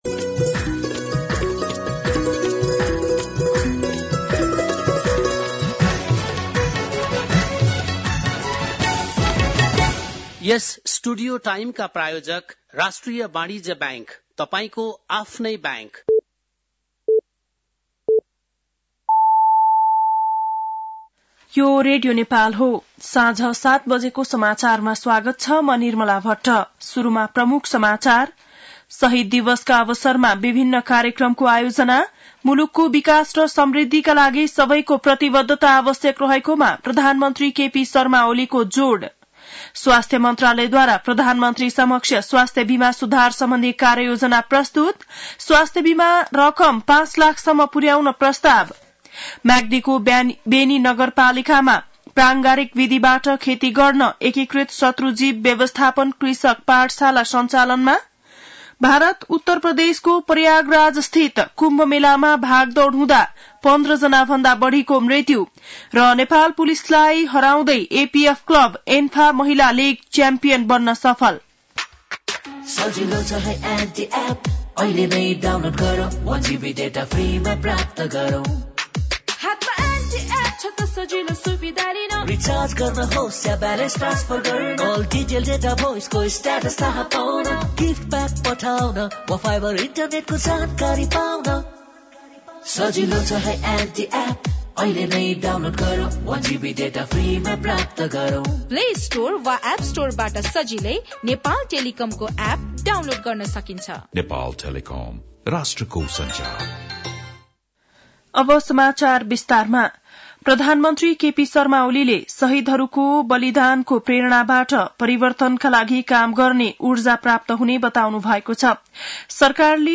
बेलुकी ७ बजेको नेपाली समाचार : १७ माघ , २०८१
7-PM-Nepali-News-10-16.mp3